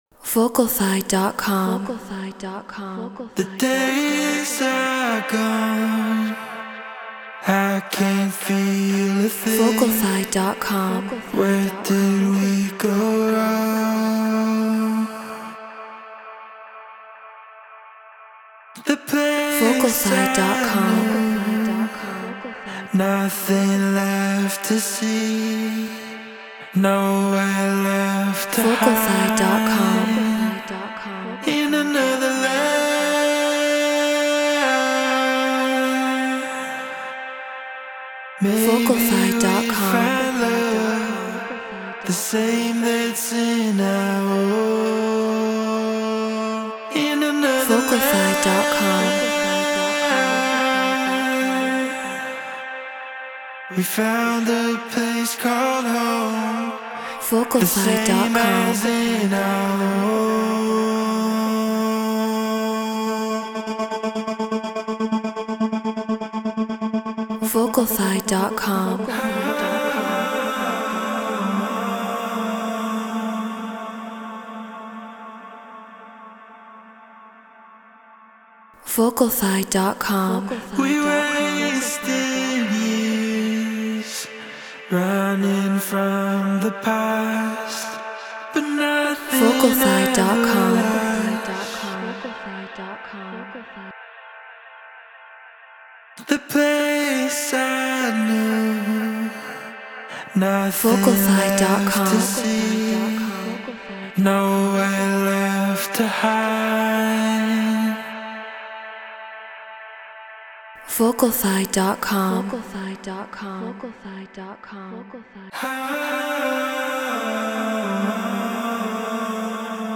House 124 BPM Dmin
RØDE NT1 Focusrite Scarlett Solo FL Studio Treated Room